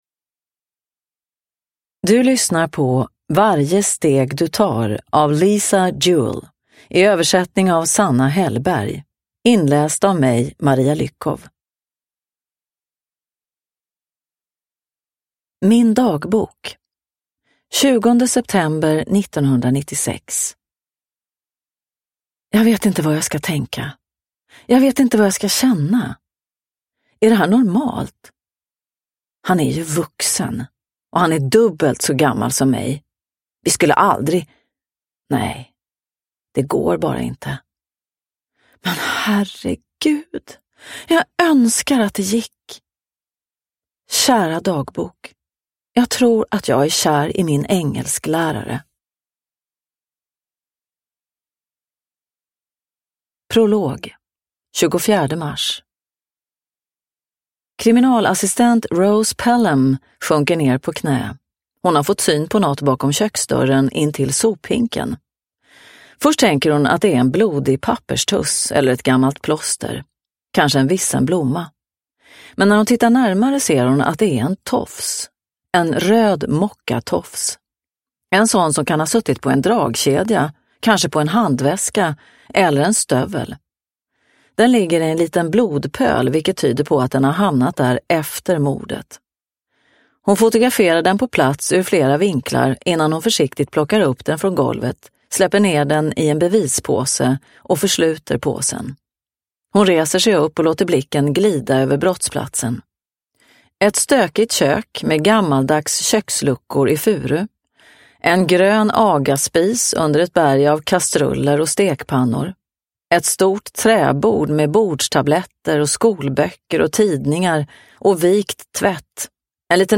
Varje steg du tar – Ljudbok – Laddas ner